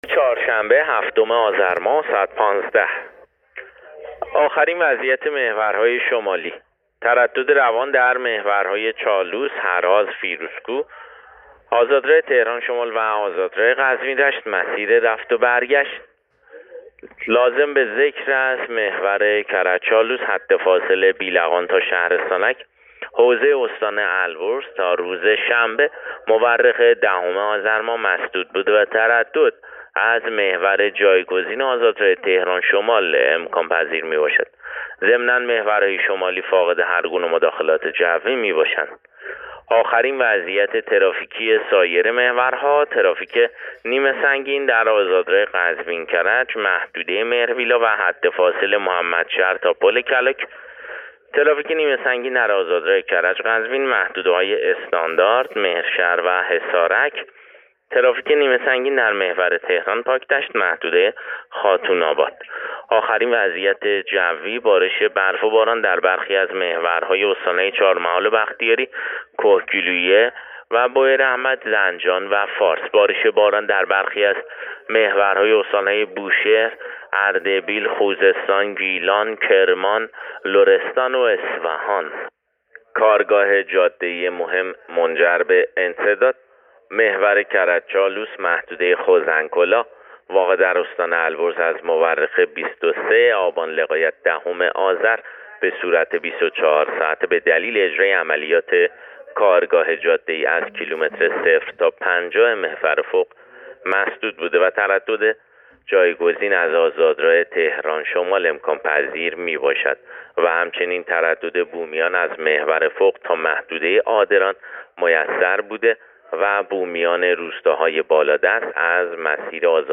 گزارش رادیو اینترنتی از آخرین وضعیت ترافیکی جاده‌ها تا ساعت ۱۵ هفتم آذر؛